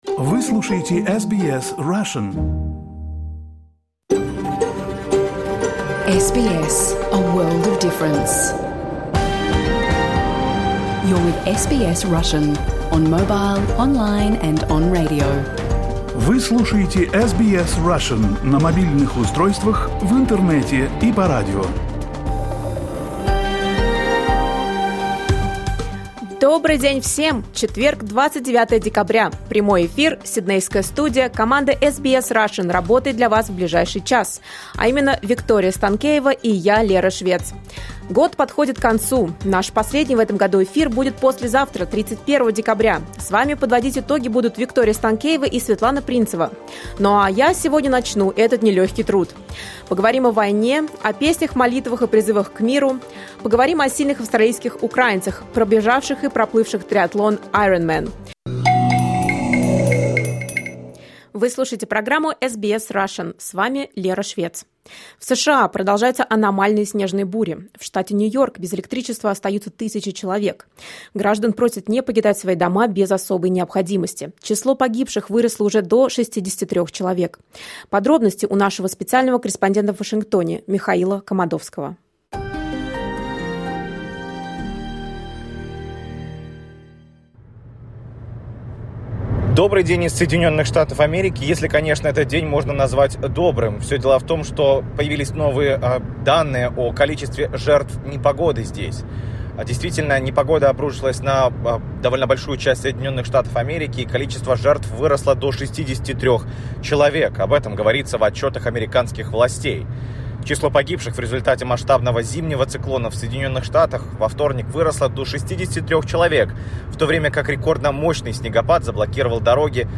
SBS Russian program — Live 29.12.2022